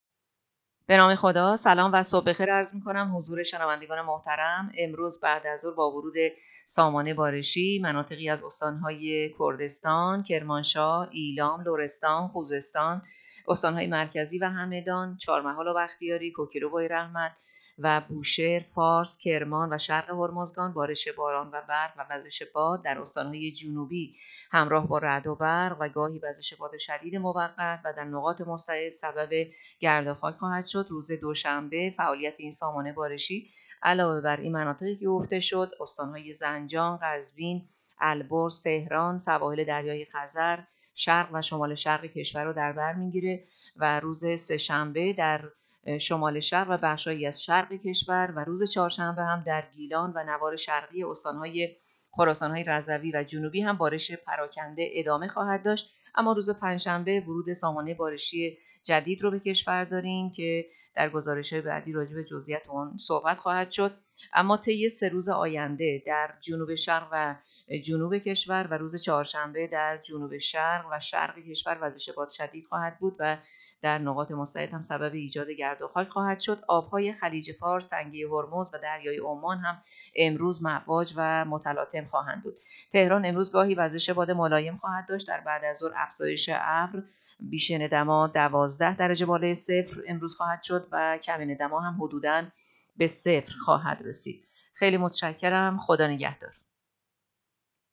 گزارش رادیو اینترنتی پایگاه‌ خبری از آخرین وضعیت آب‌وهوای ۲۸ بهمن؛